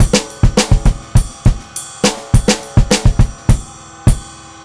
VOS BEAT 1-R.wav